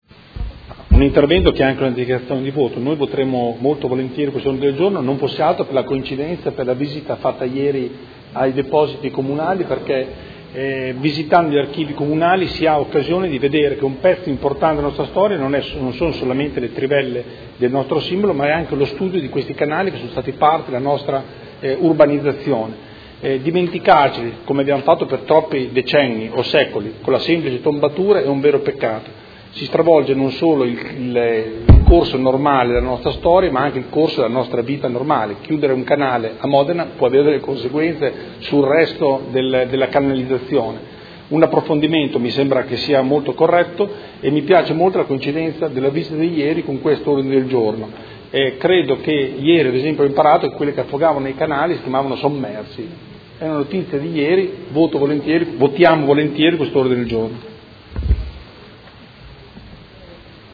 Andrea Galli — Sito Audio Consiglio Comunale
Seduta del 27/10/2016 Dibattito su Mozione 106516 e Ordine de Giorno 157637